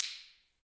Index of /90_sSampleCDs/Roland - Rhythm Section/PRC_Clap & Snap/PRC_Snaps